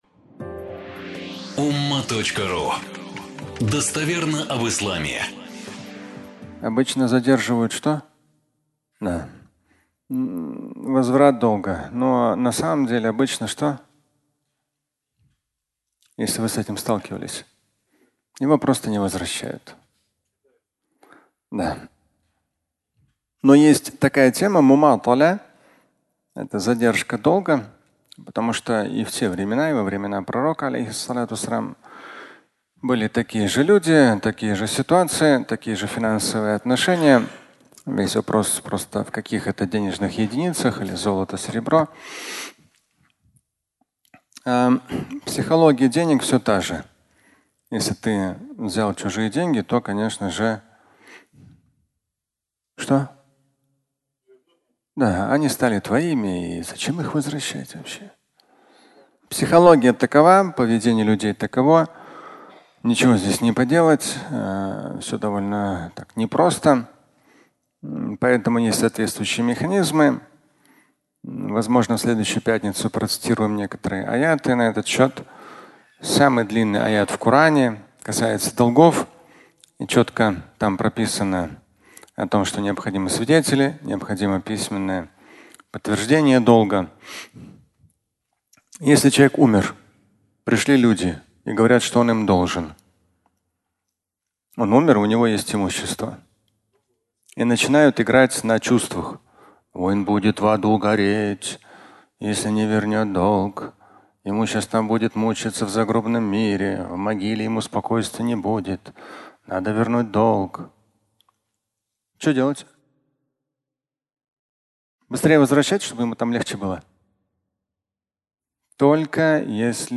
Задержка возврата (аудиолекция)